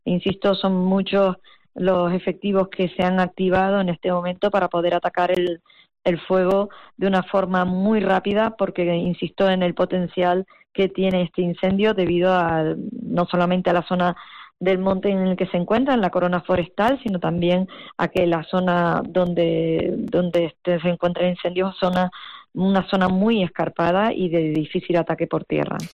Rosa Dávila, presidenta del Cabildo de Tenerife
La presidenta del Cabildo, Rosa Dávila, ha confirmado en La Mañana de COPE Canarias que el incendio tiene mucha fuerza y su extinción es muy complicada debido a la orografía del terreno.